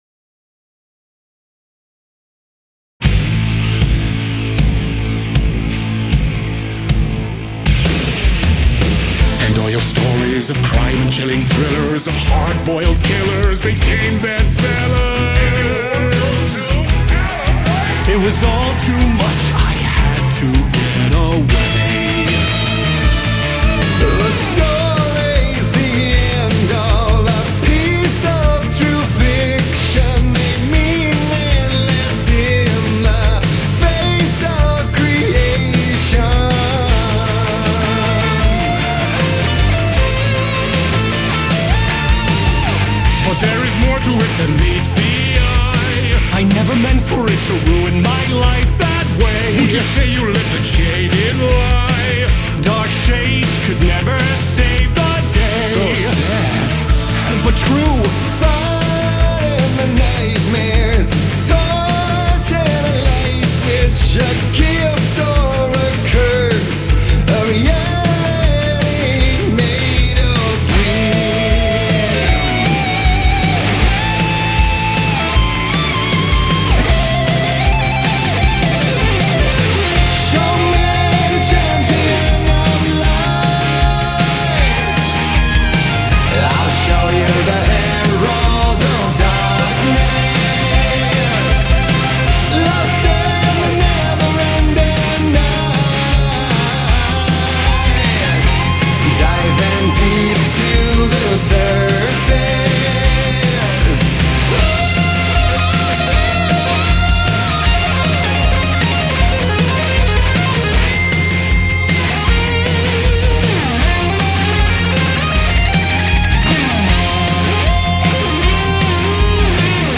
BPM156-156
Audio QualityPerfect (High Quality)
Full Length Song (not arcade length cut)